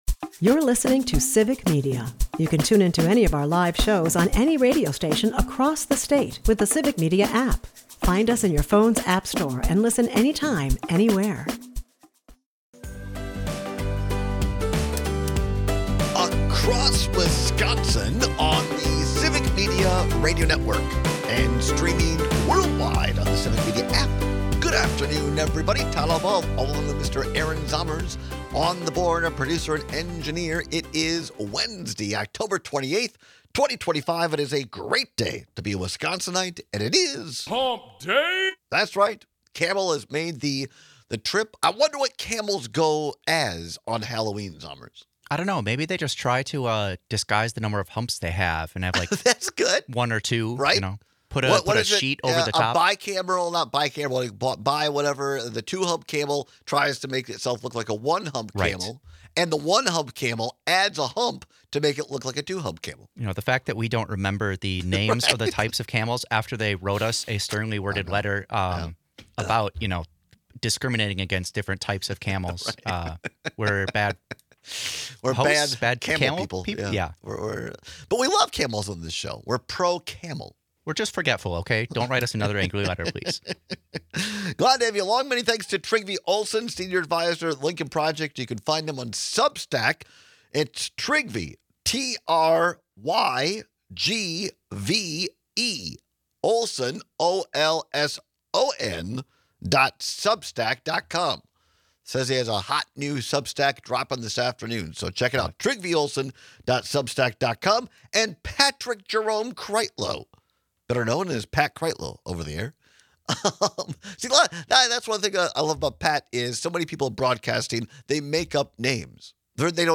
We take your calls and texts on which minor finger injury hurts more.